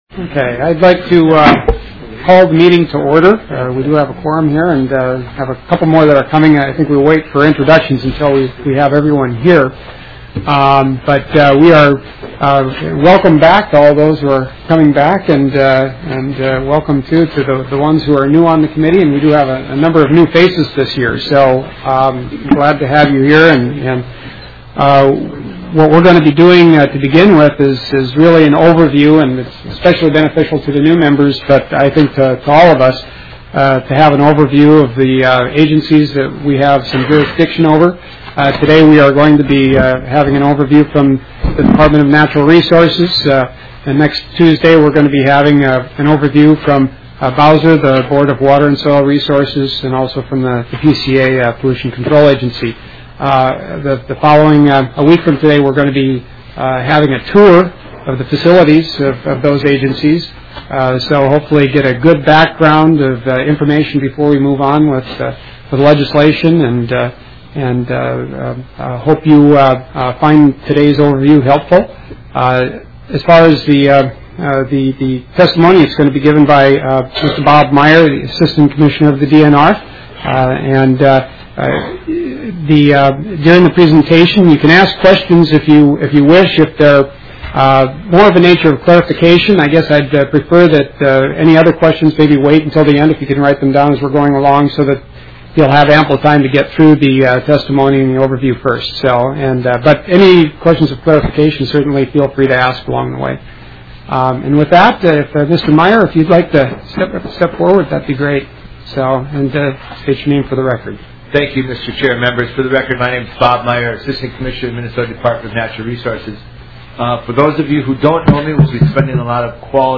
House Environment Policy and Oversight Committee